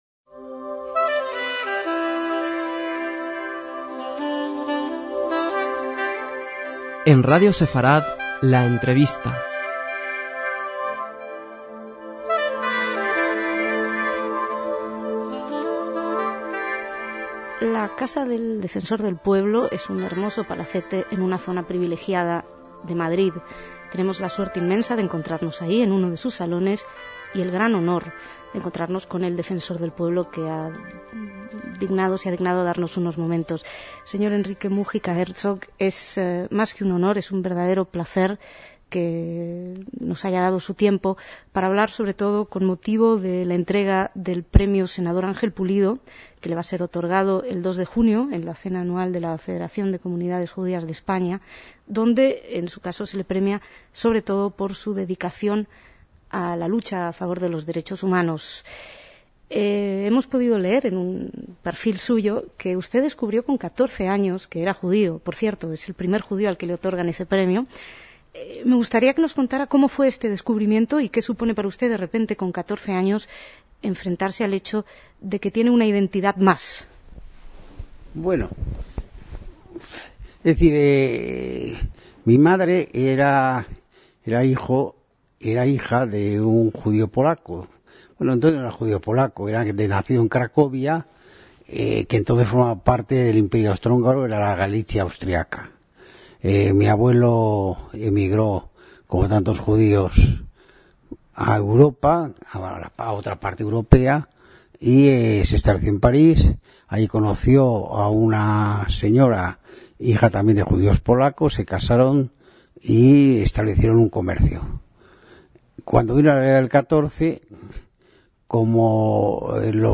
En 2008, con ocasión de haberle sigo concedido por la Federación de Comunidades Judías de España el Premio Ángel Pulido, fue entrevistado en exclusiva por Radio Sefarad y nos contó su peculiar vínculo con el judaísmo que conoció sólo de adolescente, su historia familiar, así como su carrera política y de lucha por las libertades (incluido el asesinato de su hermano Fernando en 1996 a manos de la banda terrorista ETA).